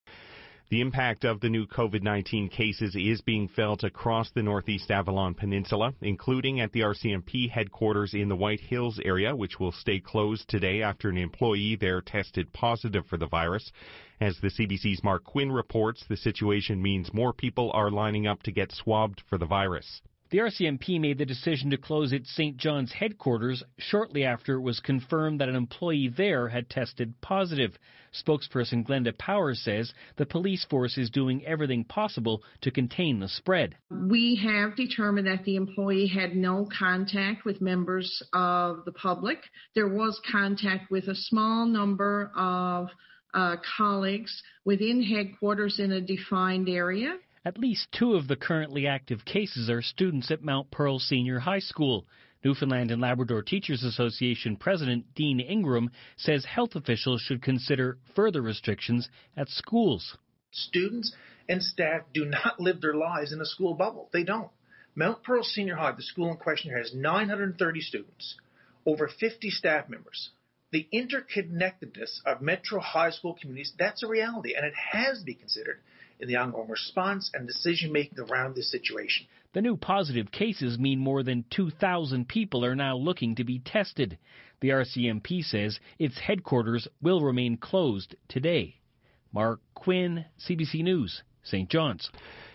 Media Interview - CBC 6am News - Feb 9, 2021